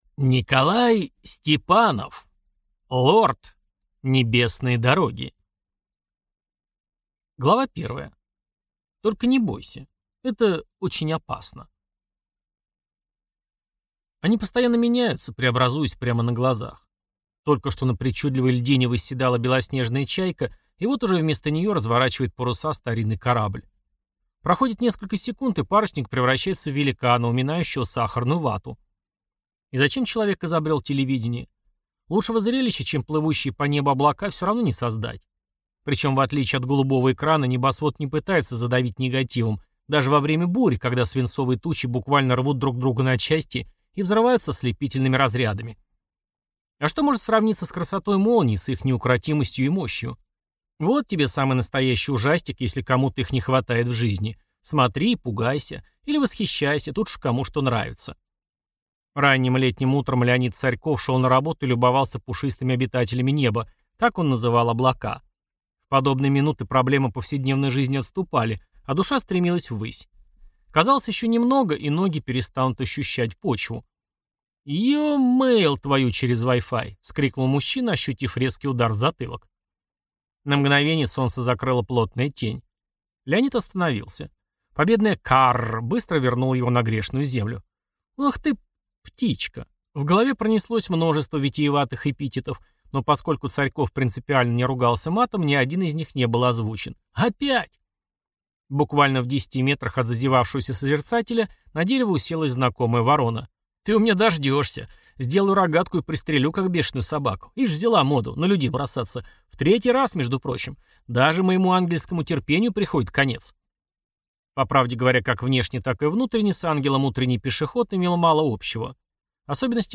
Аудиокнига Лорд. Небесные дороги | Библиотека аудиокниг